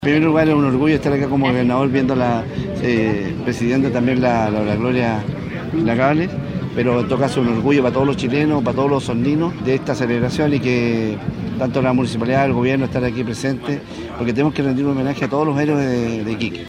Con ceremonia y desfile en Plaza de Armas de Osorno fue conmemorado un nuevo aniversario del Combate Naval de Iquique, en el mes del mar.
El Gobernador Provincial, Daniel Lilayú se mostró orgulloso de formar parte de este homenaje a las Glorias Navales.